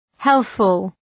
Προφορά
{‘helɵfəl}